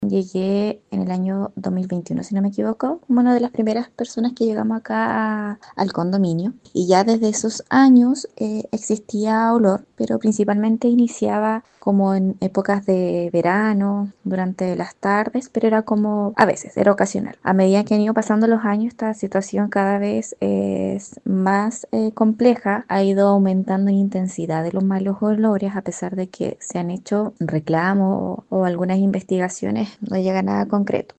Así lo señaló una de las vecinas afectadas.